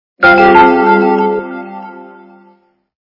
При прослушивании Звук для СМС - Windows Vista качество понижено и присутствуют гудки.
Звук Звук для СМС - Windows Vista